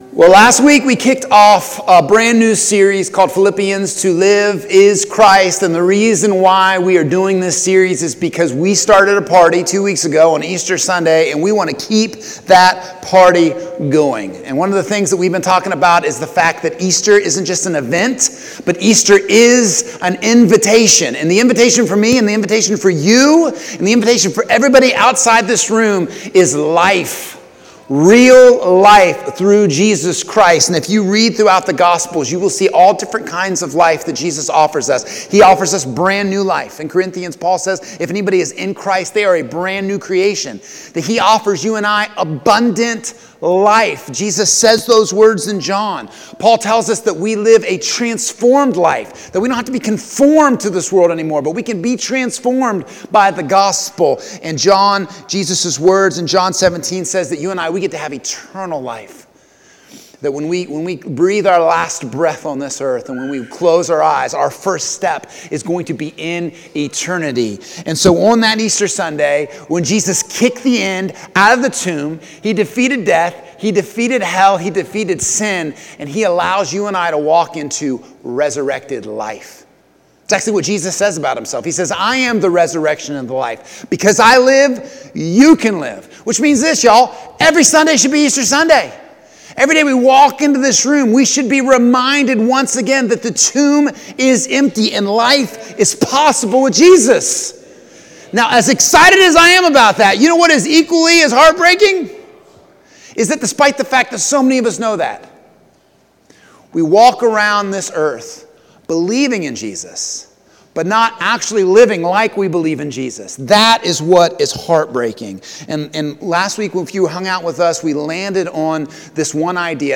Sermons | First Baptist Church of St Marys